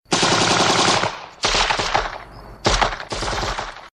• MACHINE GUN FIRES.mp3
macine_gun_fires_kc6.wav